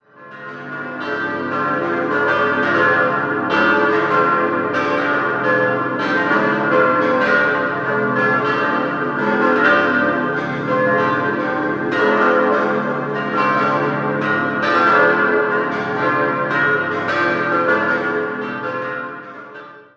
Jahrhundert. 5-stimmiges Geläute: a°-c'-d'-f'-g' Alle Glocken wurden 1931/32 von der Gießerei Grassmayr in Innsbruck gegossen.